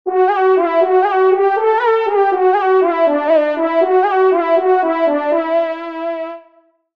FANFARE
Localisation : Somme